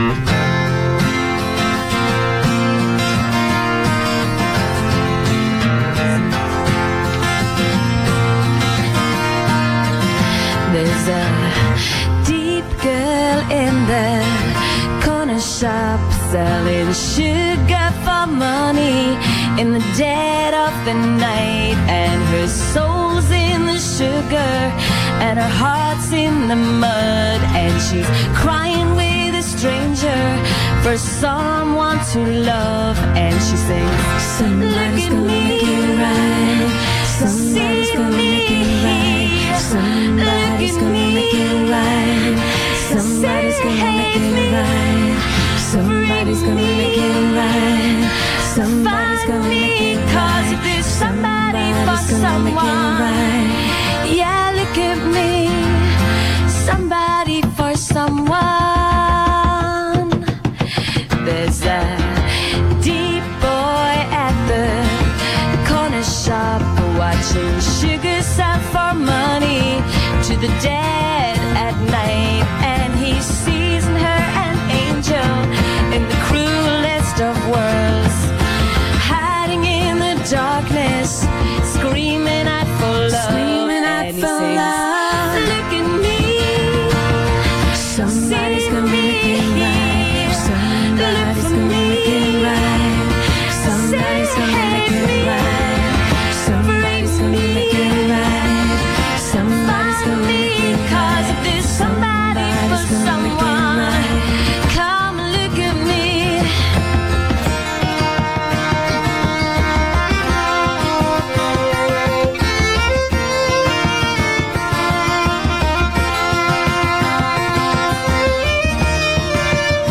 WPLJ Interview-Part 3